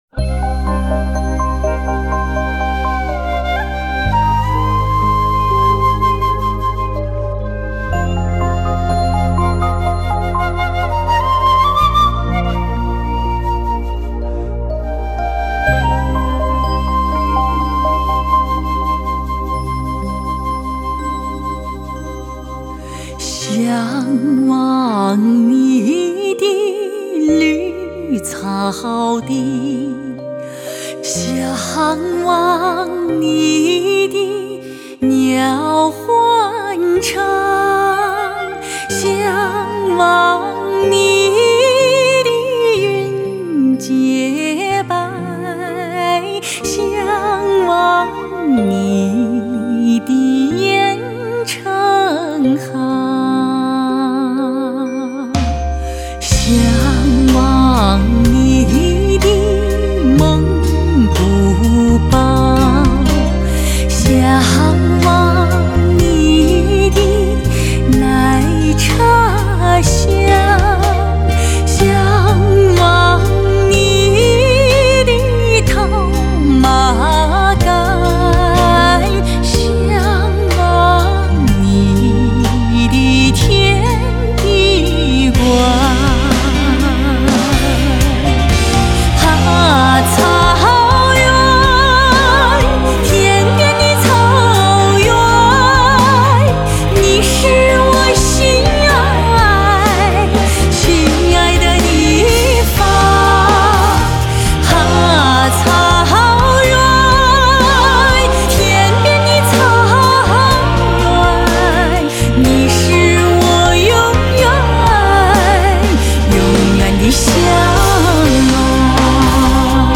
唱片类型：民族声乐
草原真情旋律拨动心弦
富有质感，既渗透草原的辽阔悠远，同时又具备专业的素养和音质。
中，我们可以听到豪放与婉转、朴实与浪漫的配合。